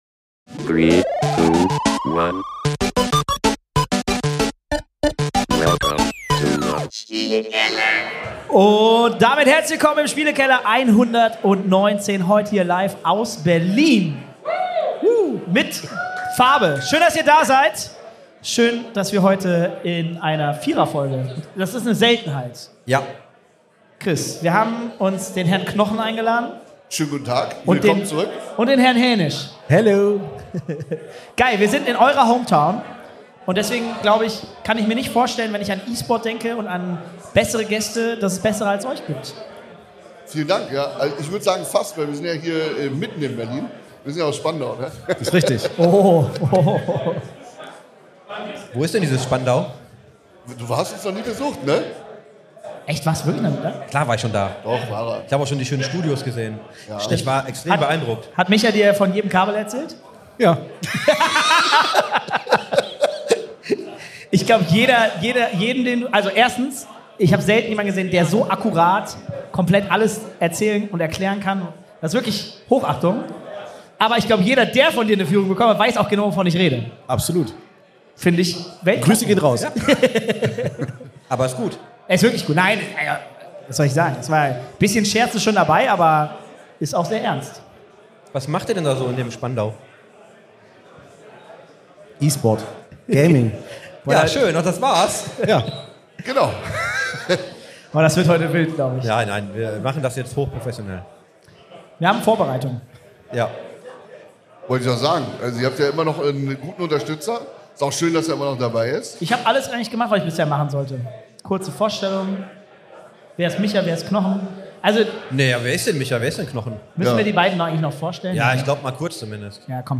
Beschreibung vor 1 Jahr Folge #119 Im Spielekeller ist ein Live-Podcast von der vierten Ausgabe der Business-LAN “The Circle”. Diese fand am 31.05. im Berliner XPERION statt.